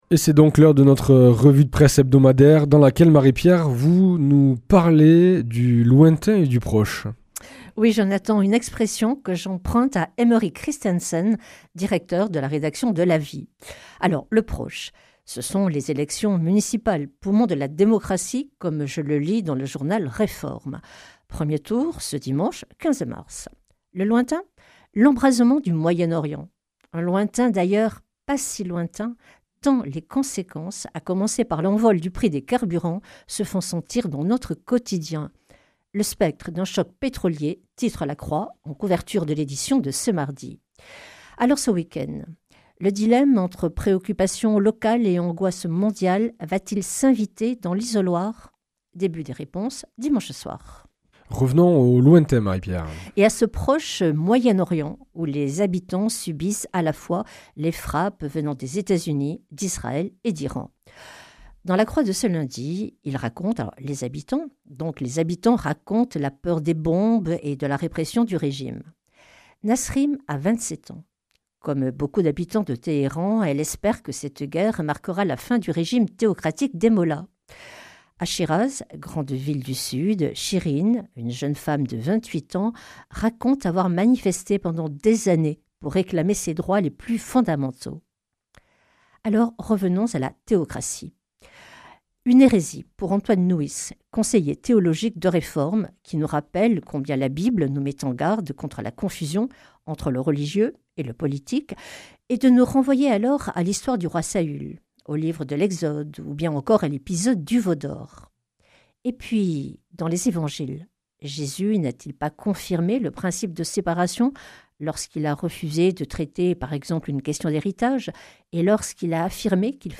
Revue de presse
Journaliste